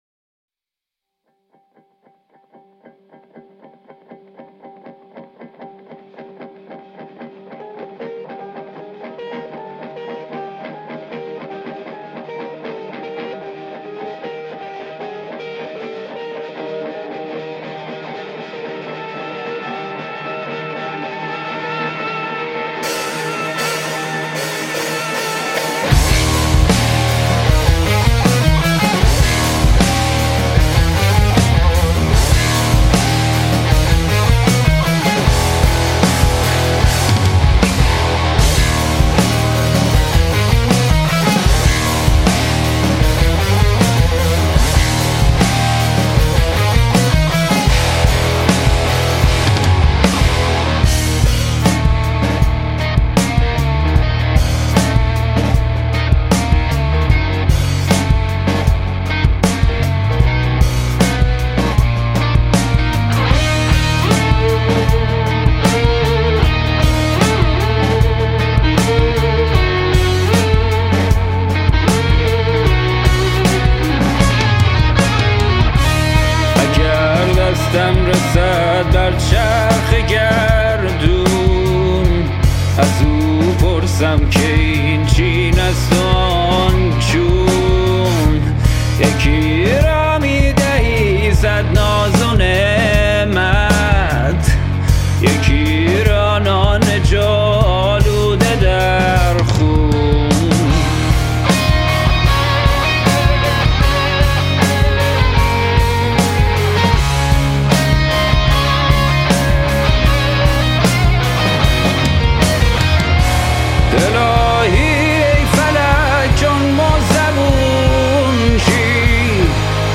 Vocals & Guitars
Drums